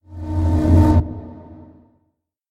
僵尸村民：哀嚎